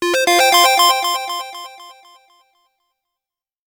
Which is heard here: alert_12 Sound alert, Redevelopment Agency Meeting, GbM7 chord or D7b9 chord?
alert_12 Sound alert